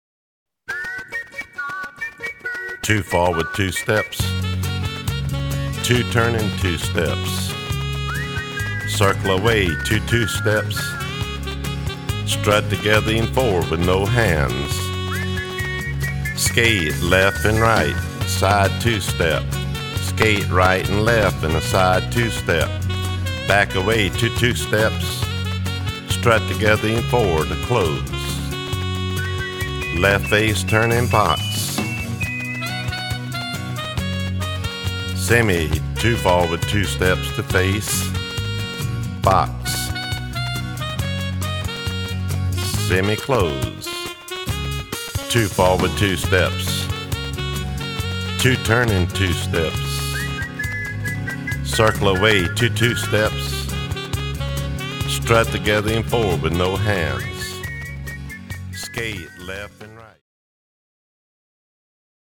Two Step